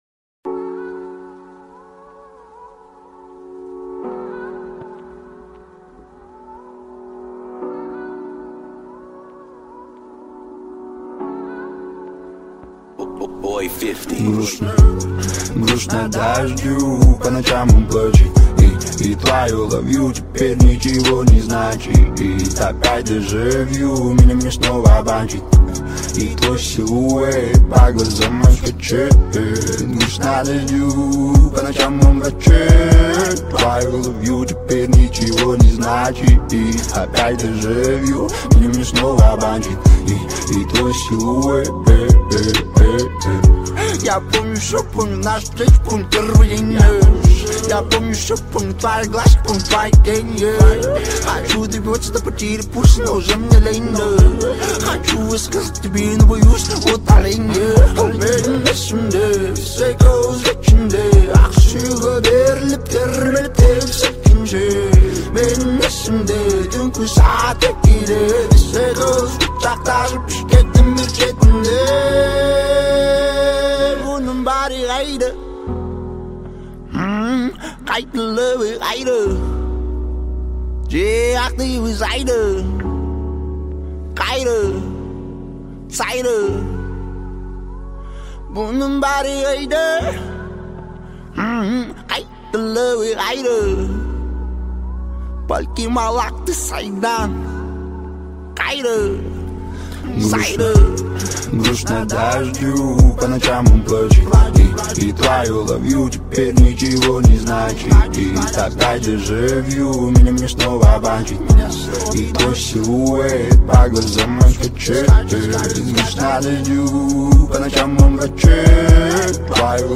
• Качество: 120 kbps, Stereo